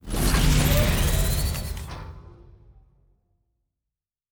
Sci-Fi Sounds / Doors and Portals / Door 9 Open.wav
Door 9 Open.wav